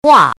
怎么读
guà
gua4.mp3